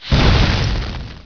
lavaburn.wav